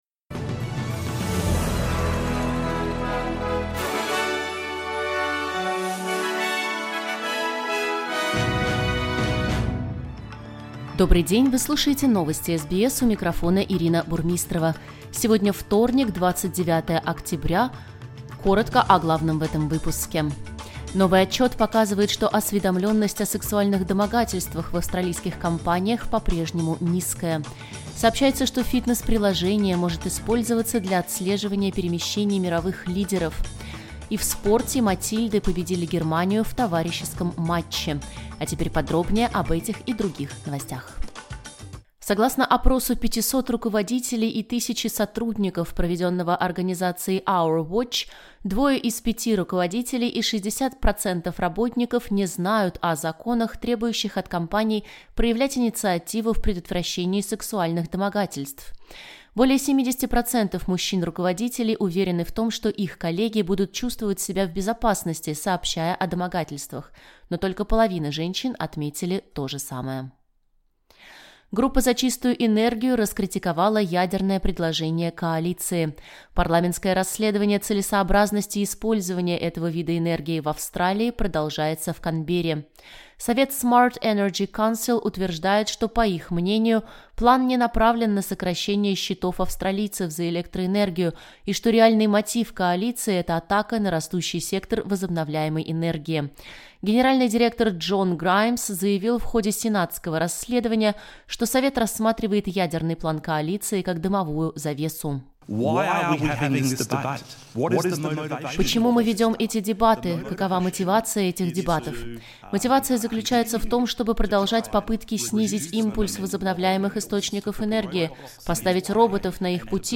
Новости SBS на русском языке — 29.10.2024